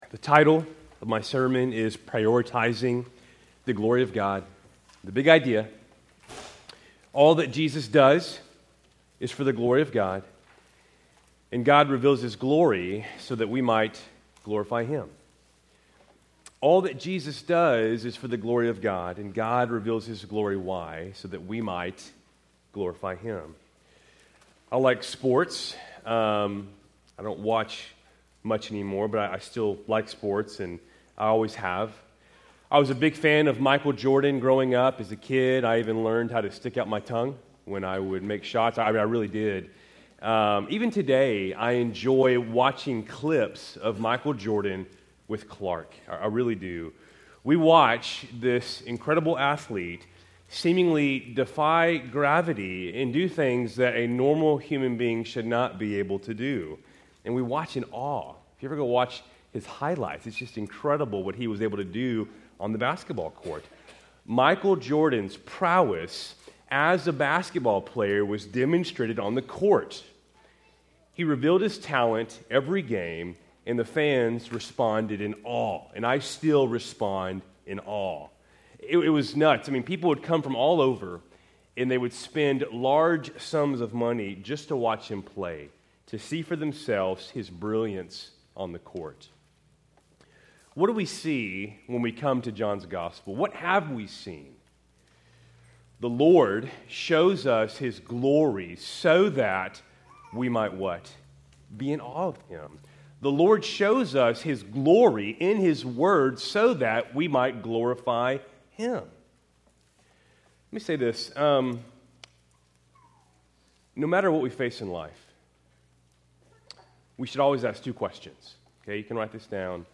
Keltys Worship Service, February 9, 2025